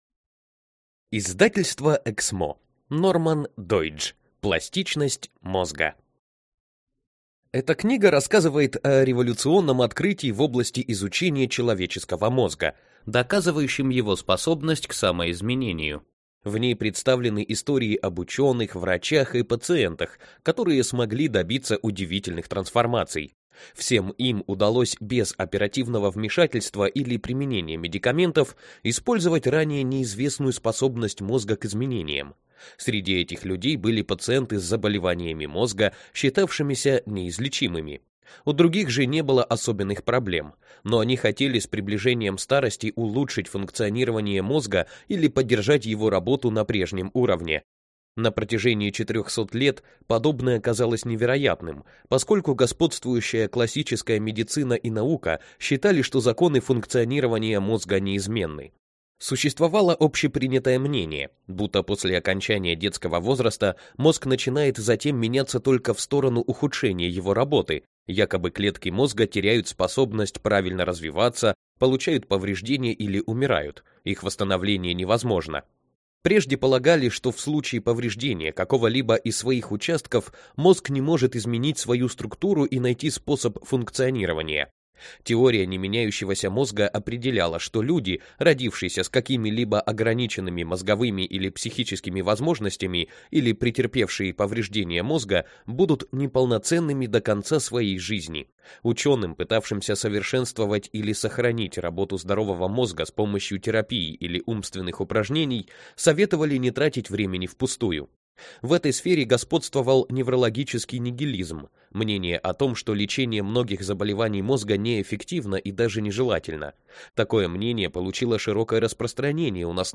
Аудиокнига Пластичность мозга.
Aудиокнига Пластичность мозга.